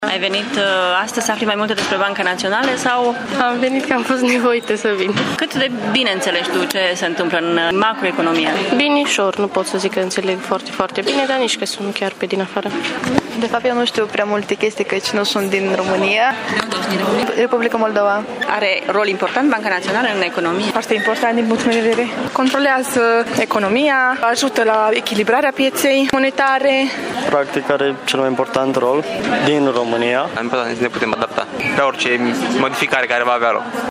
Studenții au venit la workshop cu sau fără voia lor, însă ceea ce știu e că Banca Centrală are un rol important în stabilitatea monetară: